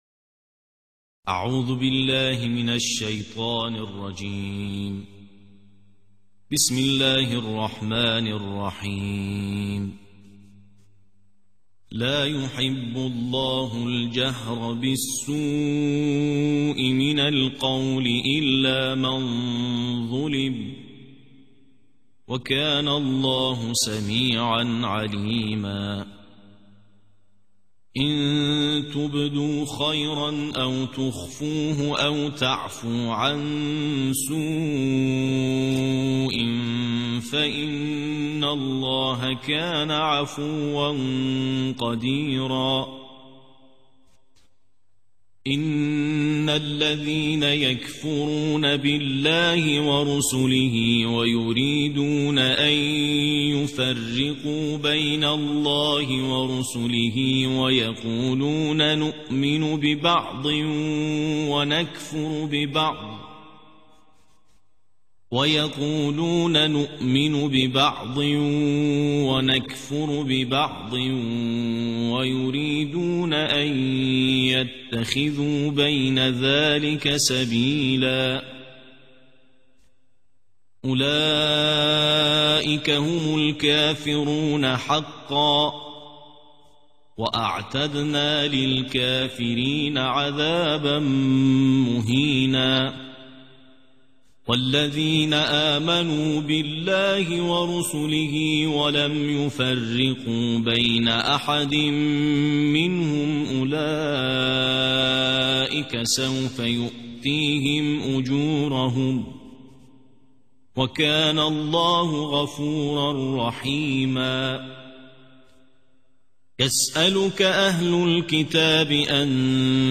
ترتیل جزء ششم